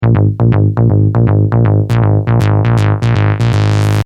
| dark acid bass arpege |